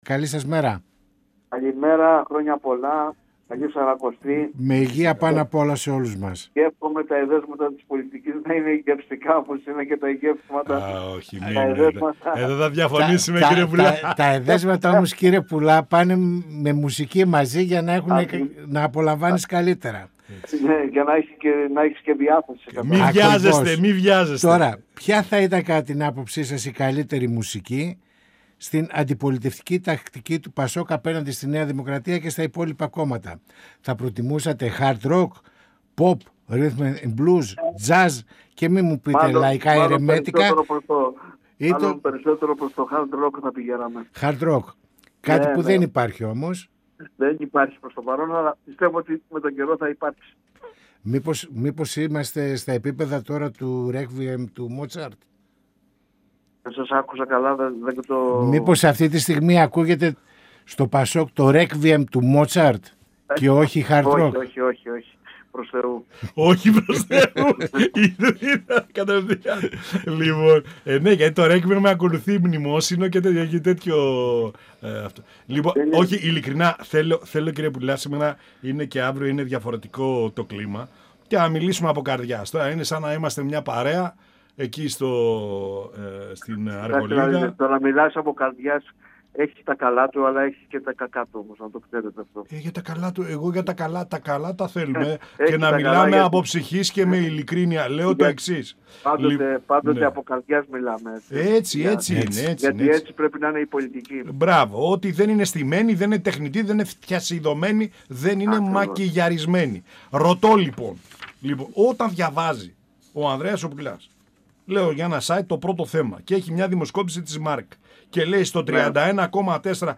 Στην προοπτική του προοδευτικού χώρου και το ισχνό ενδεχόμενο συνεργασίας των κομμάτων της κεντροαριστεράς πριν τις εκλογές, καθώς και το επερχόμενο Συνέδριο του ΠΑΣΟΚ, αναφέρθηκε ο Βουλευτής του ΠΑΣΟΚ Ανδρέας Πουλάς, μιλώντας στην εκπομπή «Πανόραμα Επικαιρότητας» του 102FM της ΕΡΤ3.